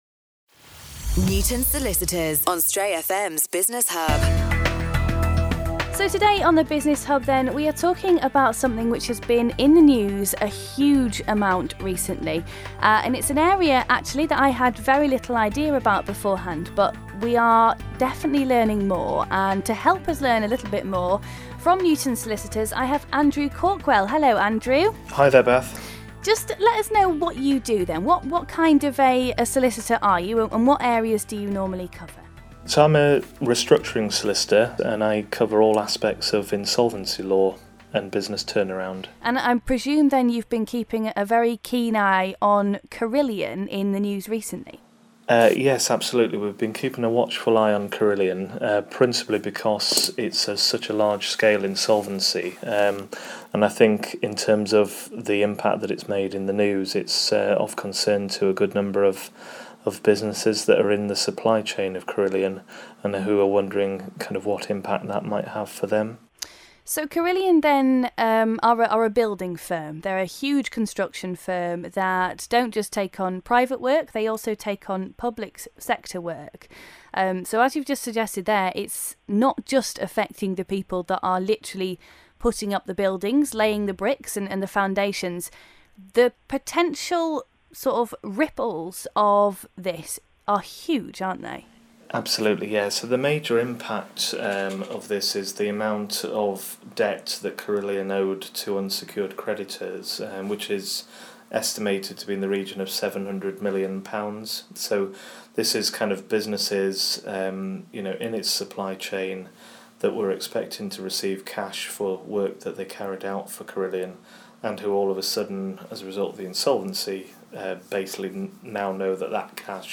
Talks Carillion Insolvency on Stray FM